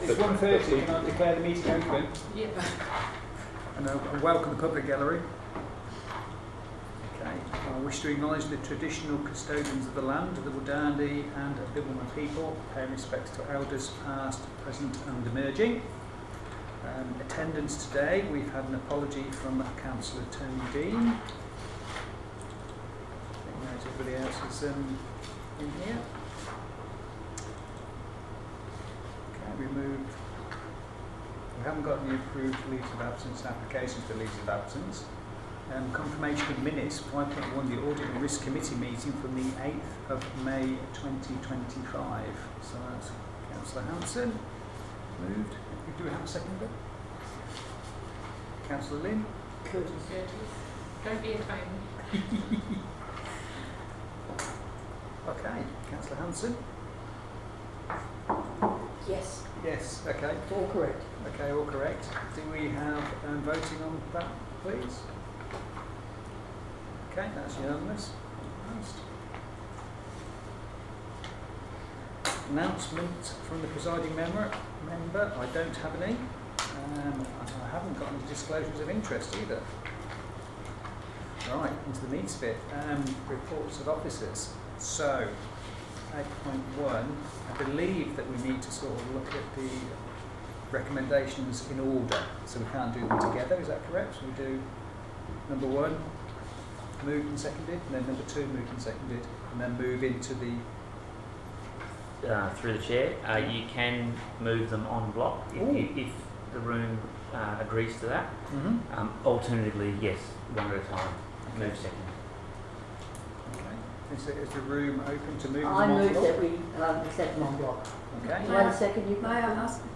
recording-public-copy-audit-and-risk-committee-meeting-3-december-2025.mp3